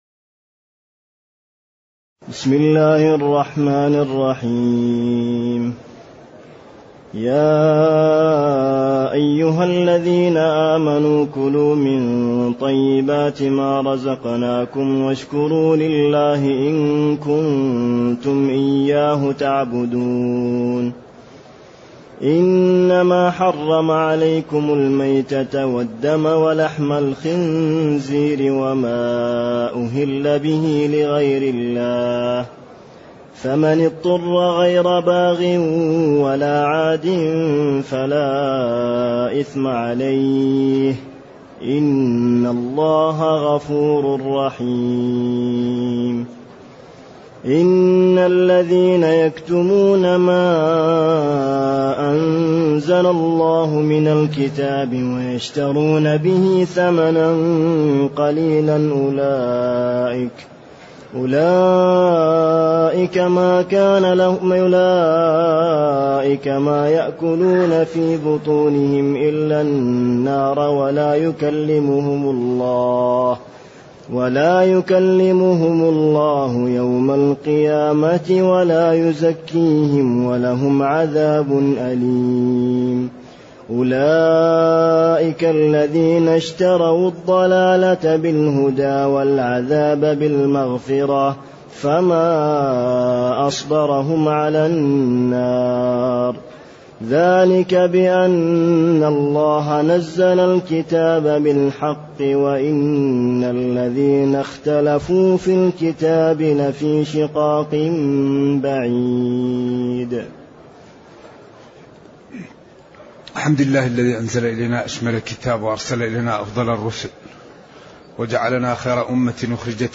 تاريخ النشر ١٧ رجب ١٤٢٨ هـ المكان: المسجد النبوي الشيخ